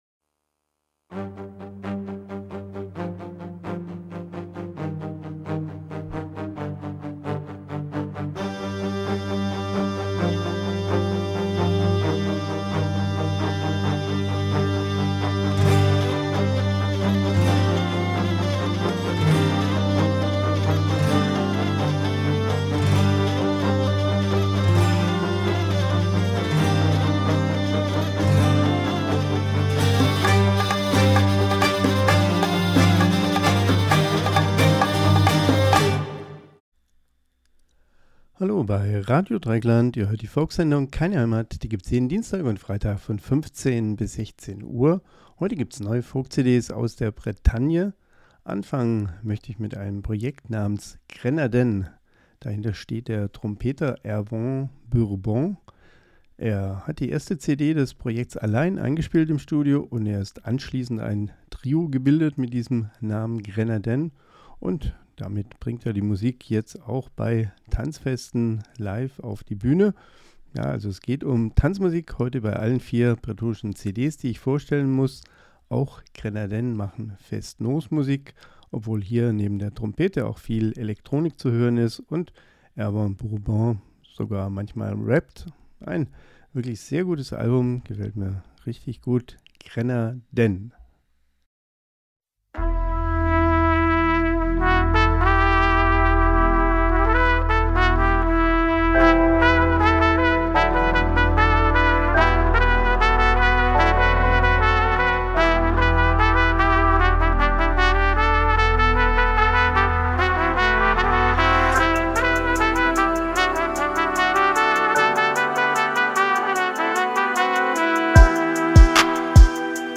Keine Heimat (Euro Folk)